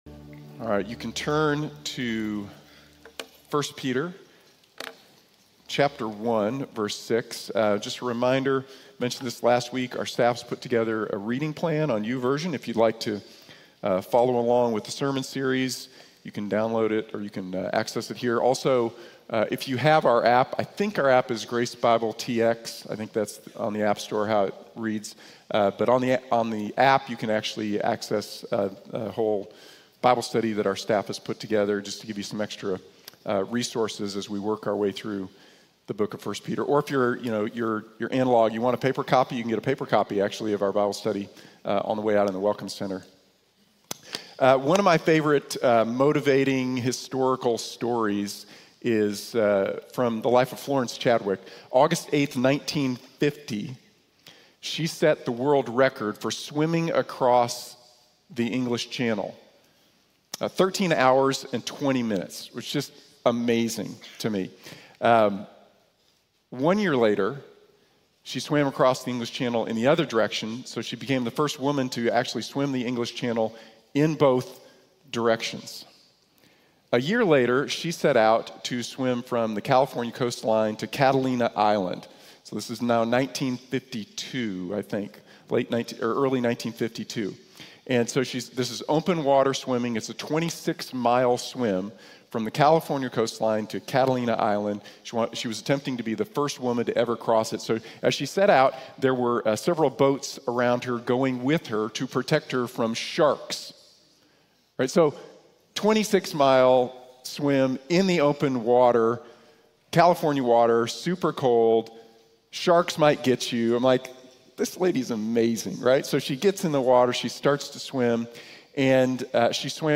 Alégrate En Tu Sufrimiento | Sermón | Iglesia Bíblica de la Gracia